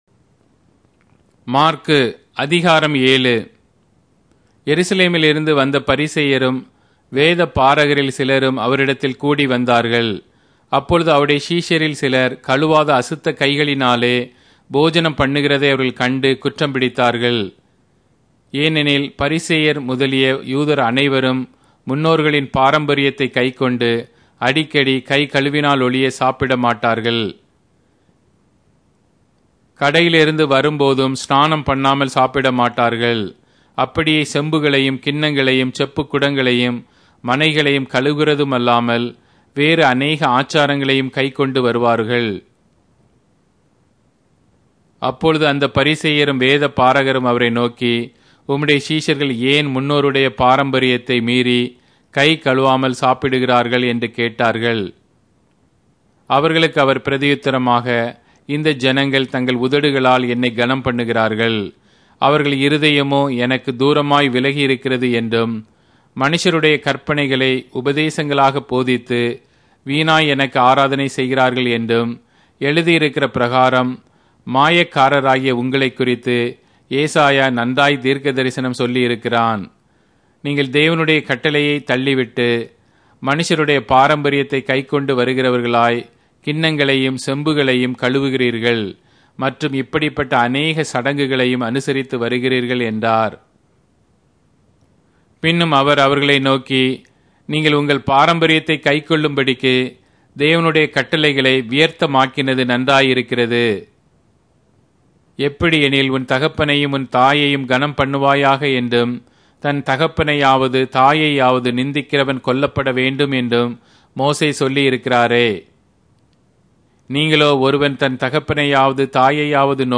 Tamil Audio Bible - Mark 4 in Tev bible version